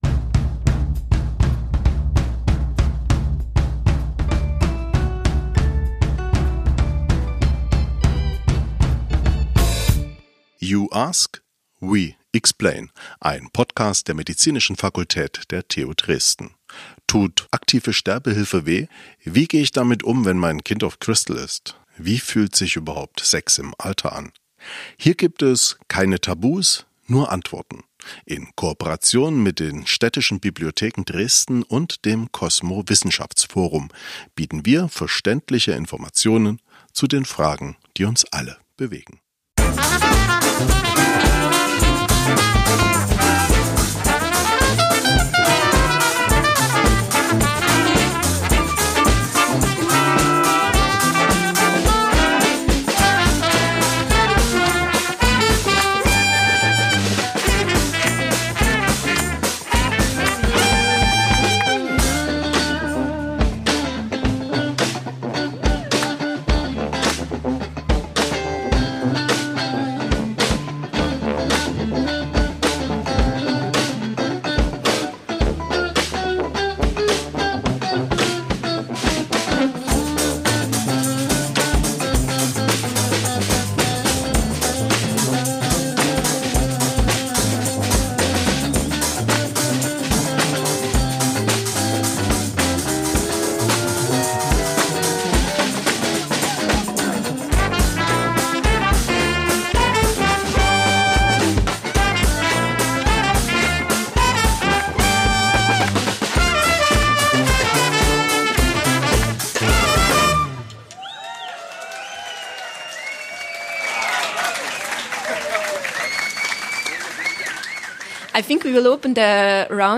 Es verspricht eine spannende Diskussion über Dresden, Europa und die Welt zu werden in der nächsten TUD Lectures+.